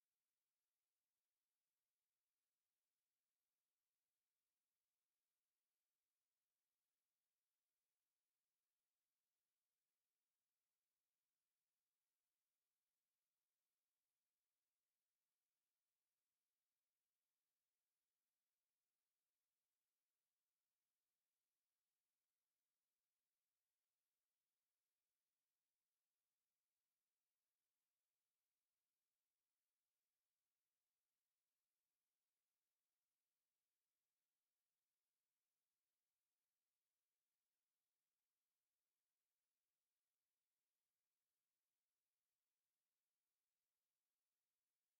• Sachgebiet: Hörspiele